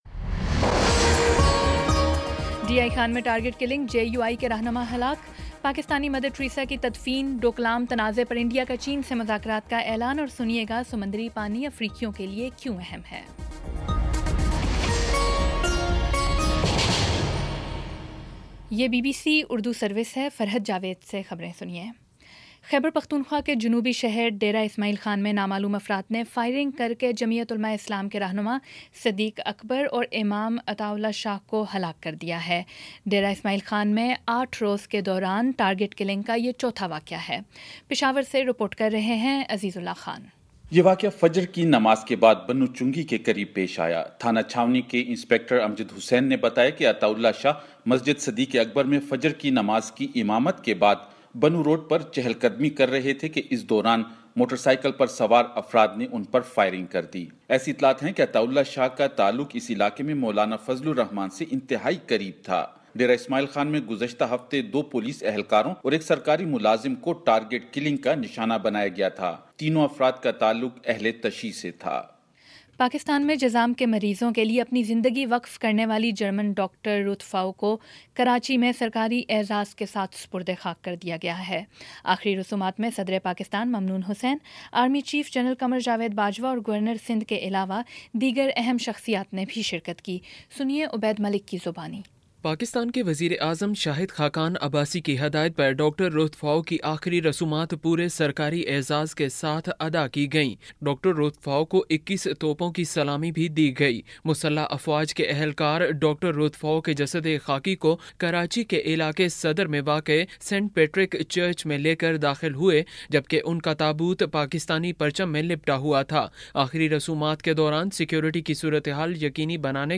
اگست19 : شام پانچ بجے کا نیوز بُلیٹن